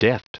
Prononciation du mot deft en anglais (fichier audio)
Prononciation du mot : deft